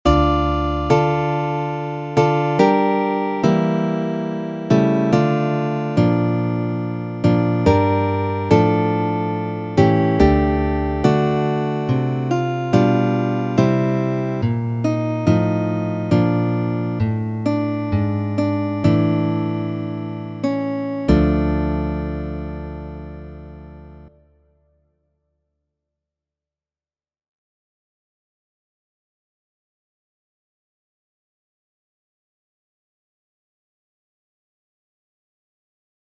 Muziko: Mallonga